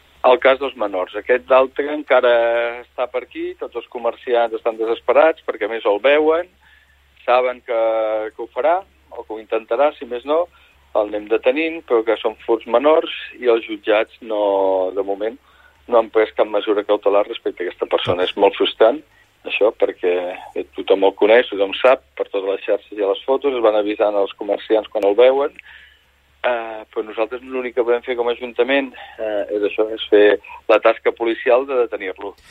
I tot i la sensació d’inseguretat que es pot percebre, l’alcalde del municipi, Òscar Aparicio, en una entrevista al Supermatí reconeix que ells només “poden fixar-se en les dades, tot i que és complicat”.